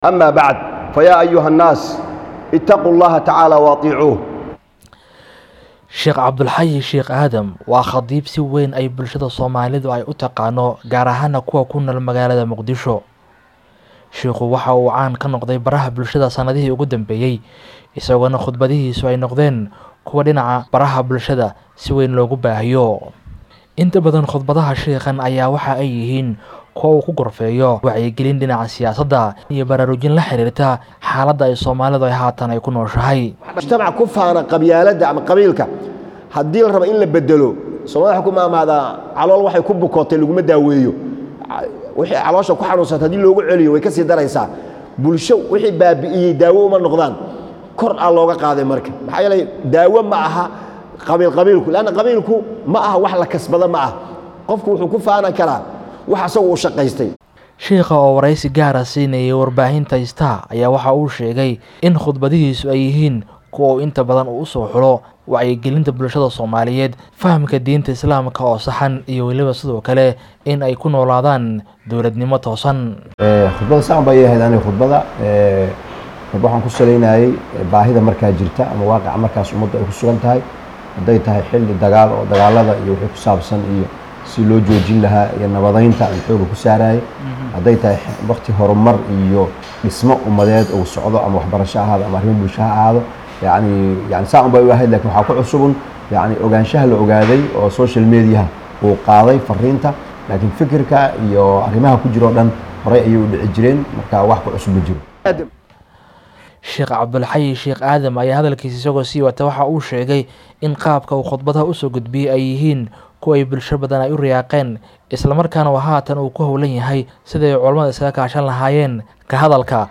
Waraysi gaar ah